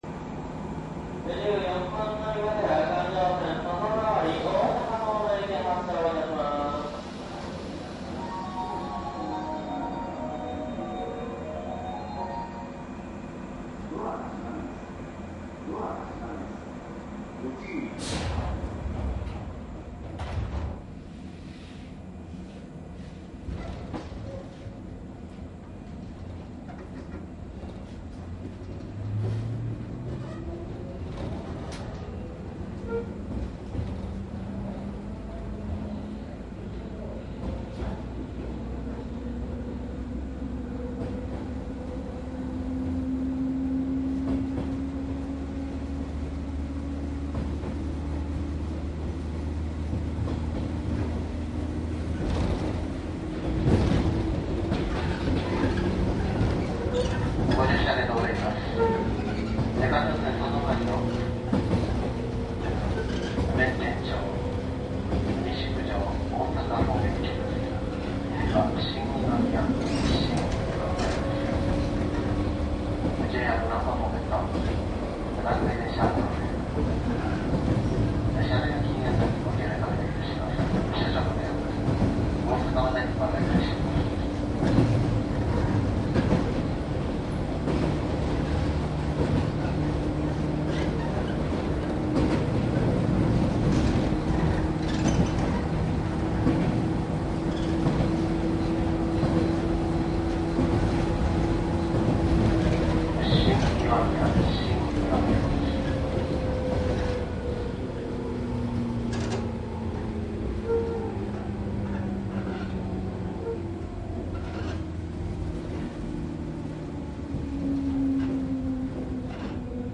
鉄道走行音CD★西日本１０３系★JR大阪環状線
商品説明外回りでモハ102の初期型主電動機にて、モハ102はCPやMGの音が主体です。
ＪＲ西日本１０３系 抵抗制御 モハ１０2－５４０
大阪環状線・外回り 各停 天王寺→天王寺
サンプル音声 モハ１０2－５４０.mp3
※収録機材は、ソニーDATと収録マイクソニーECM959を使用しております。